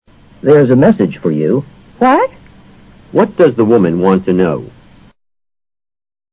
托福听力小对话【94】What?